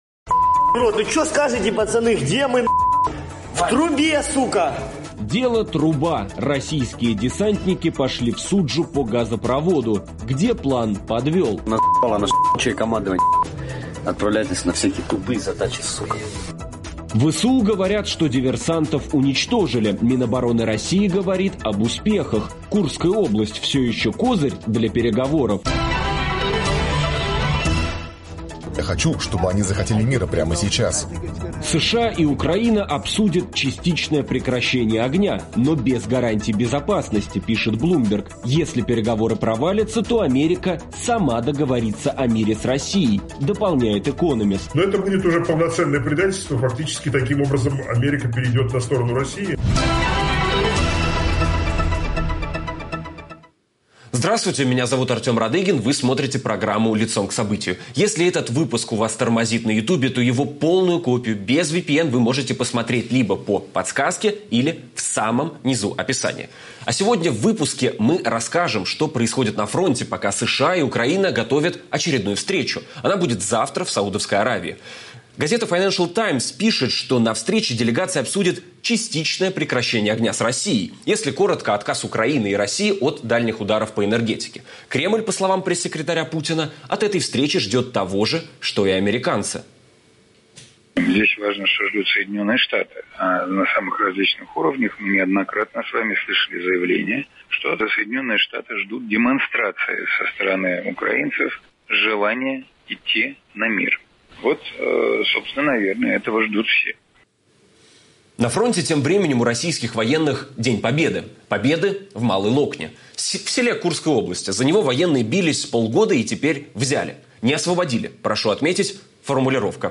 Чего ждать от предстоящих переговоров в Саудовской Аравии и каковы позиции на фронте? Об этом говорим с политологом